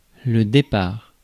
Prononciation
Prononciation France: IPA: [de.paʁ]